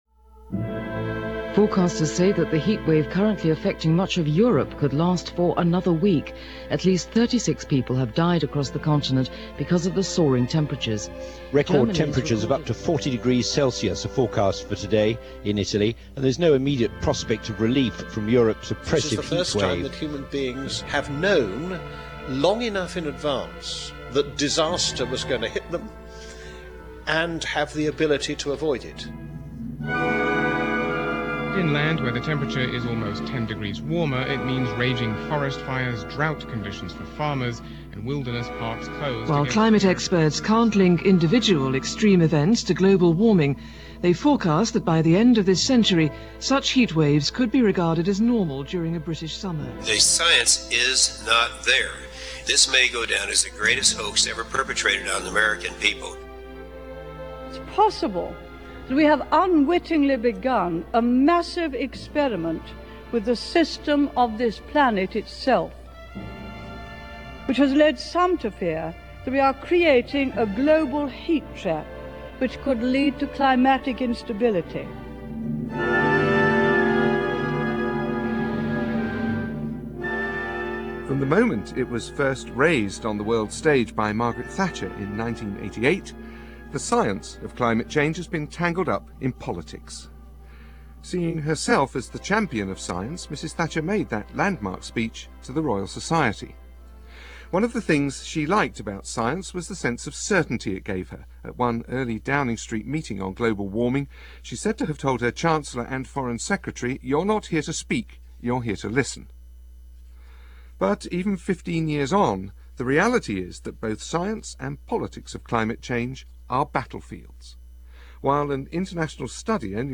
Certainly longer than this documentary, produced by BBC Radio 4 , did in 2004.